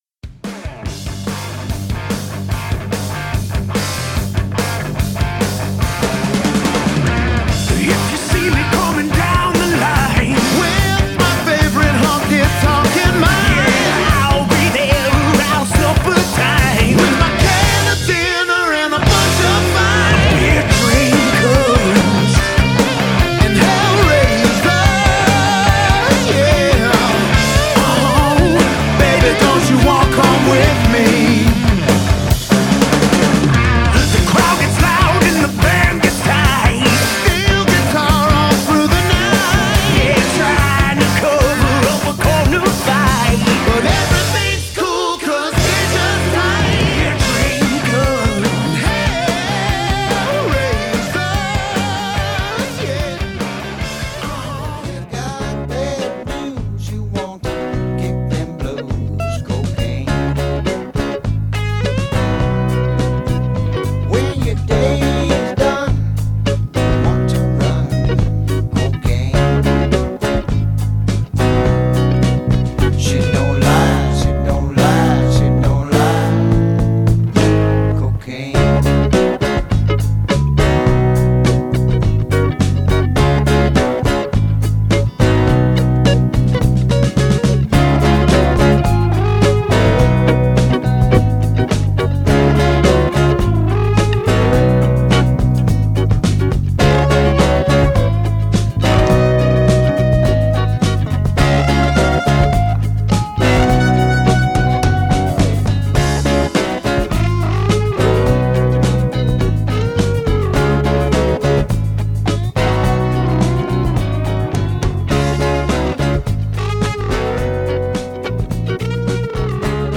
slide guitar